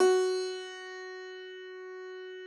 53l-pno12-F2.wav